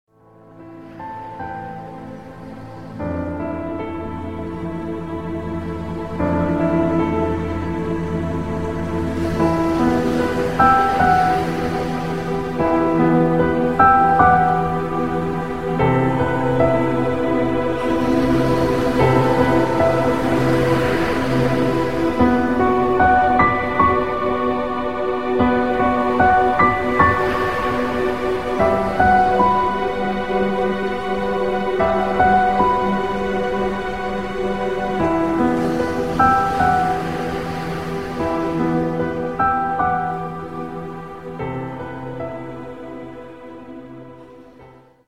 Tags: sad